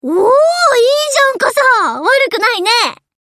Ship Voice Asashimo Kai Ni Repair.mp3
Ship_Voice_Asashimo_Kai_Ni_Repair.mp3